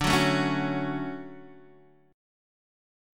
DM7 chord